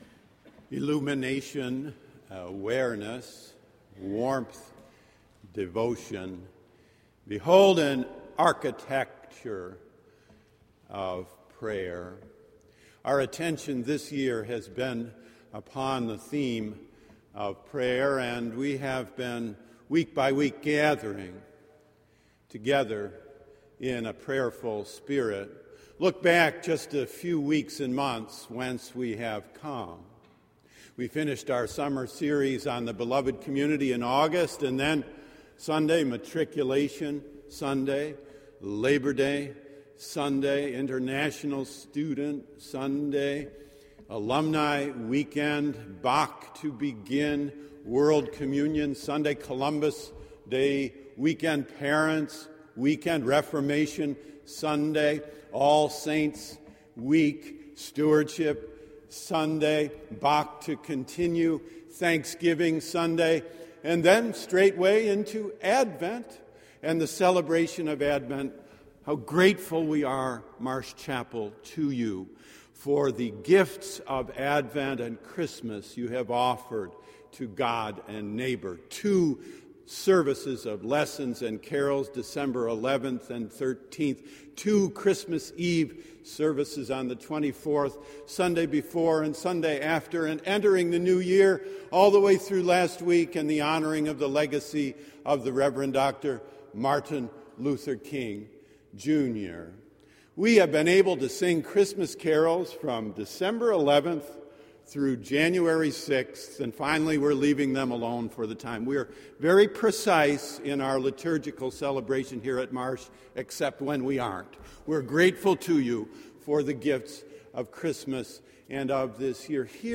By Marsh Chapel Click here to listen to the full service Luke 4:14-21 Click here to listen to the sermon only Preface To illumine the imagination by the beauty of God.